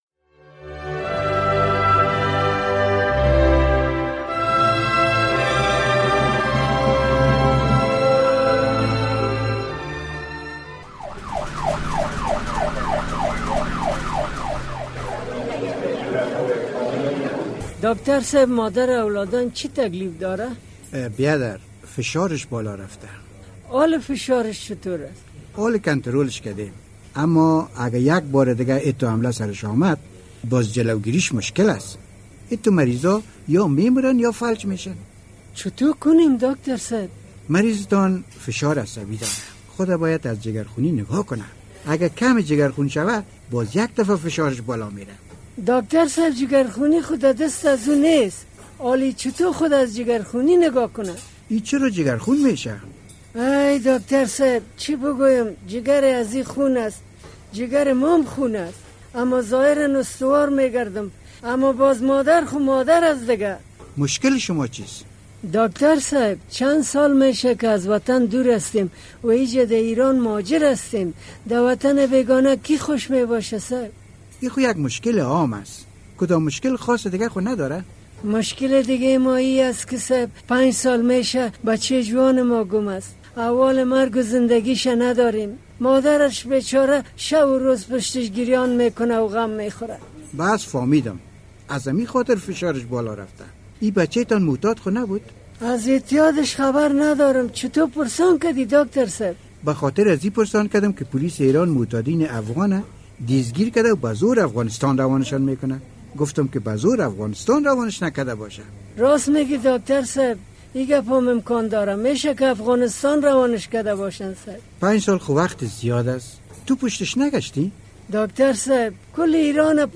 درامه: شیر را پولیس چرا دستگیر کرد؟